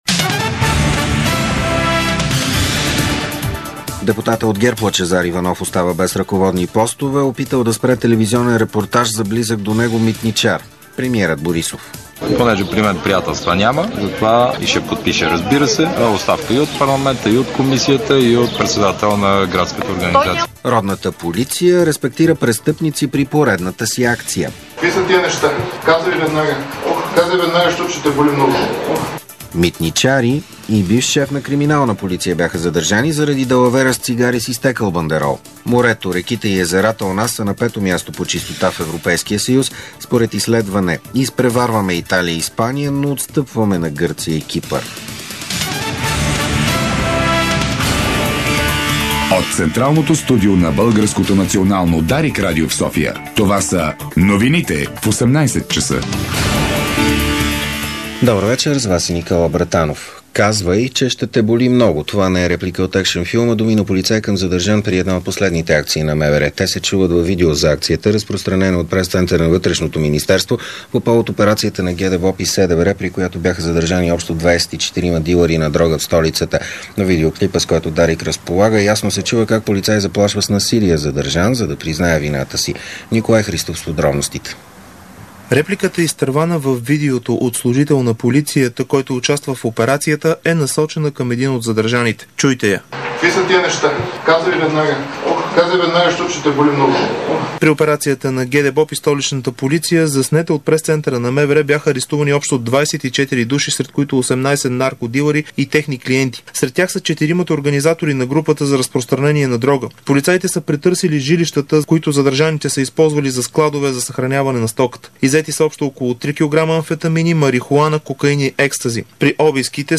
Обзорна информационна емисия - 07.07.2010